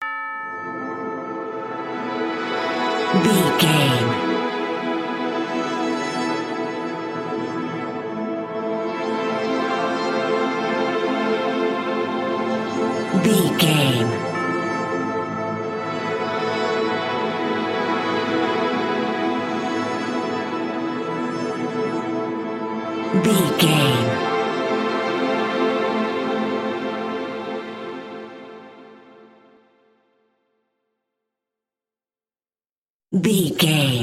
Aeolian/Minor
SEAMLESS LOOPING?
drums
electric guitar
bass guitar